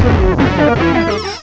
cry_not_drifblim.aif